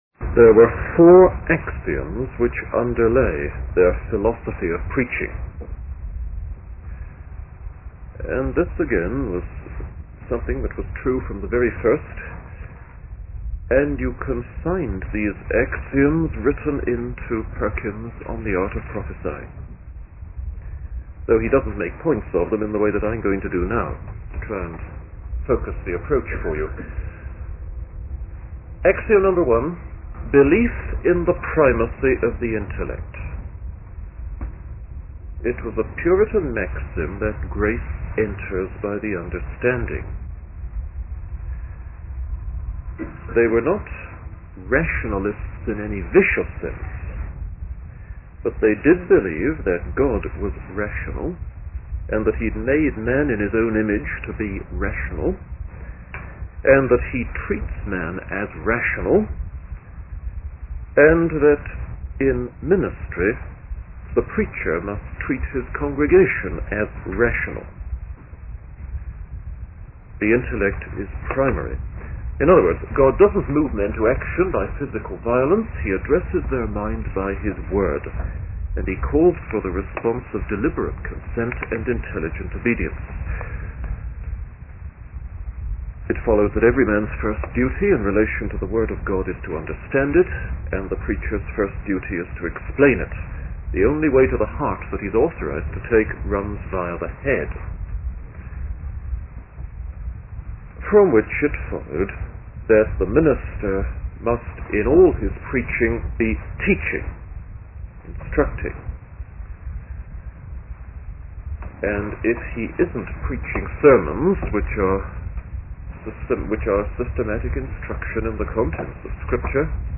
In this sermon, the preacher discusses the certainty of God's truth, emphasizing that it is confirmed by God's solemn oath. He highlights how the Lord repeatedly commands and persuades the wicked to turn from their sinful ways.